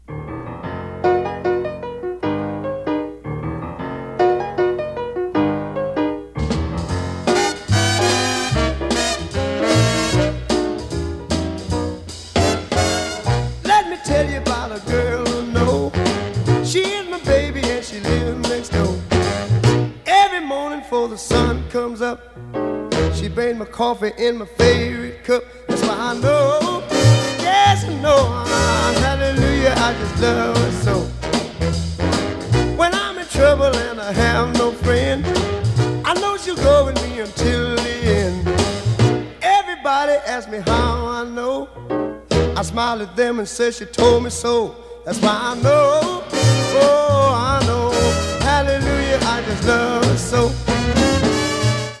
• Качество: 144, Stereo
пианино